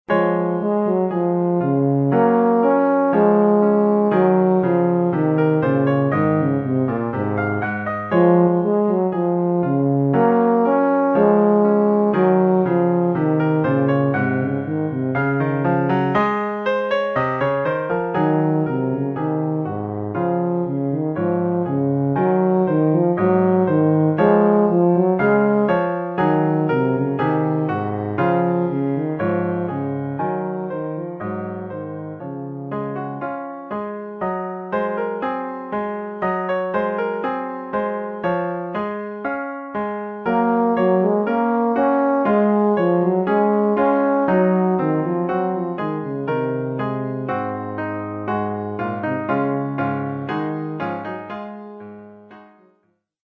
Oeuvre pour saxhorn basse /
euphonium / tuba et piano.